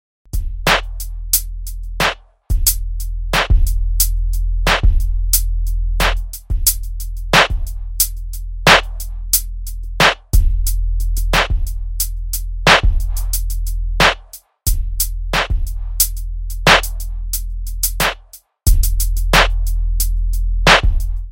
Tag: 90 bpm Hip Hop Loops Drum Loops 3.59 MB wav Key : E